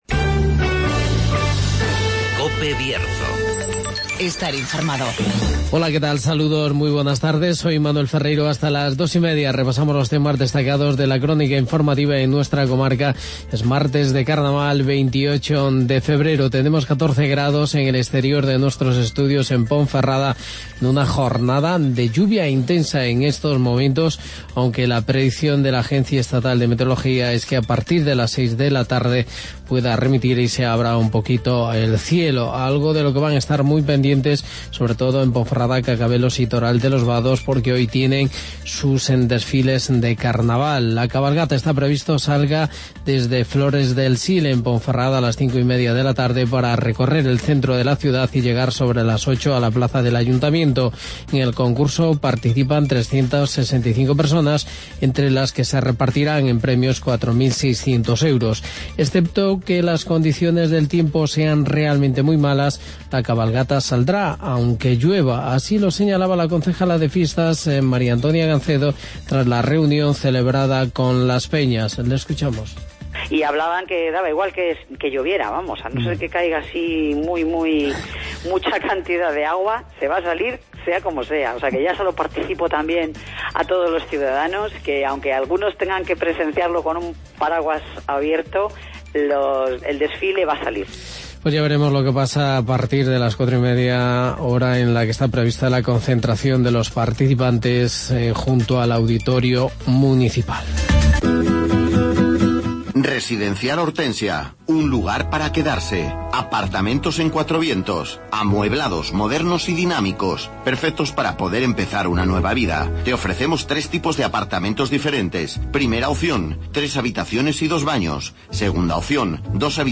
AUDIO: Mediodía Bierzo de hoy martes, 28 de febrero de 2017Informativo